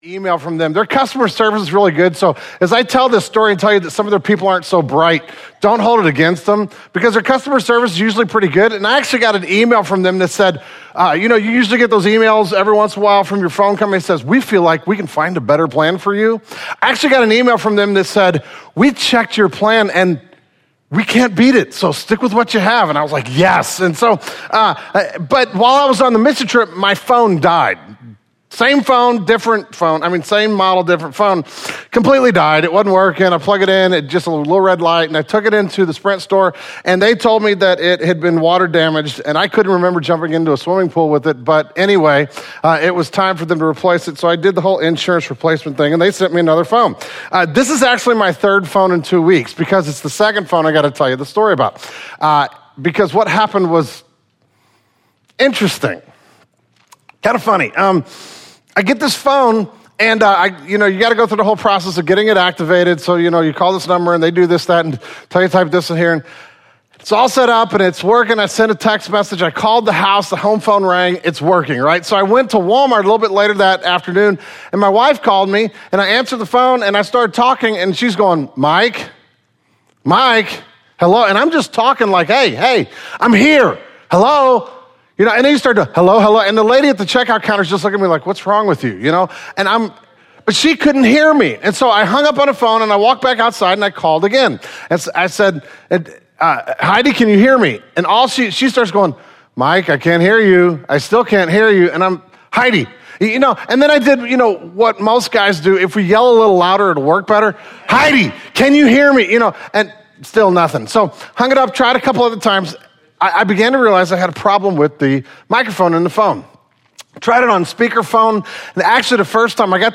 The journey through Nehemiah ends with this sermon.